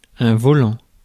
Ääntäminen
IPA : [ˈbæd.mɪn.tən]